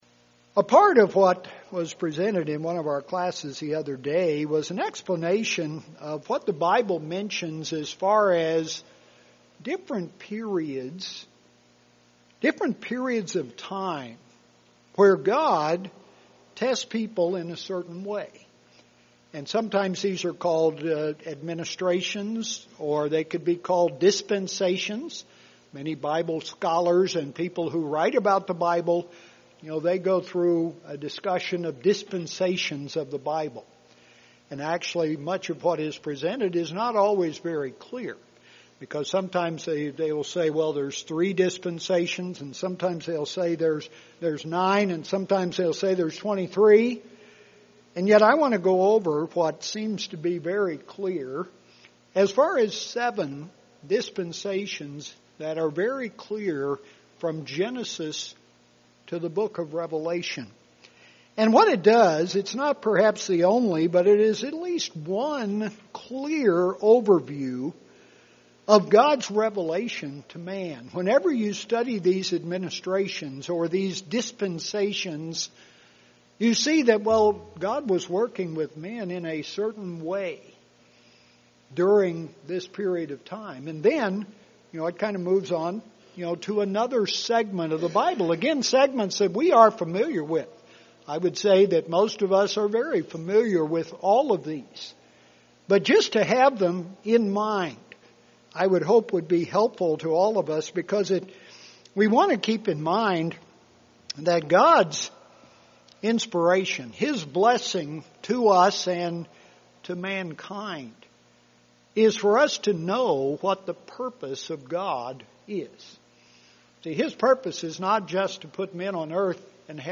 Sabbath Services Transcript This transcript was generated by AI and may contain errors.